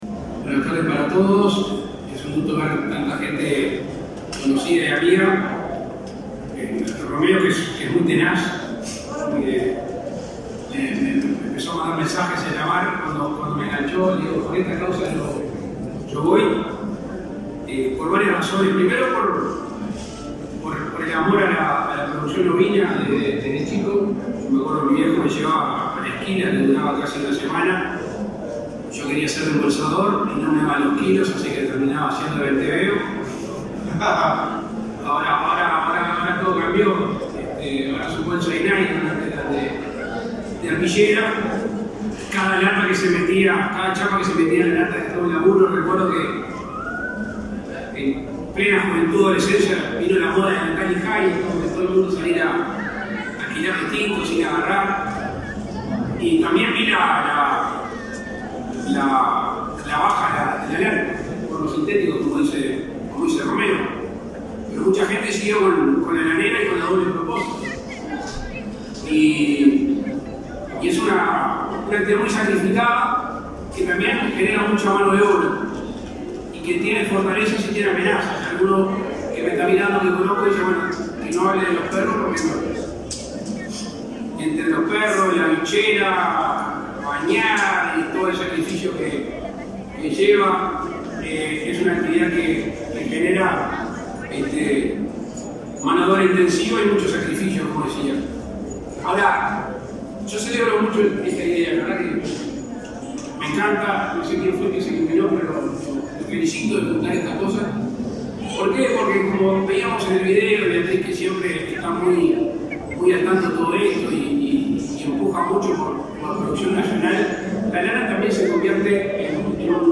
Palabras del presidente de la República, Luis Lacalle Pou, en la Expo Prado 2022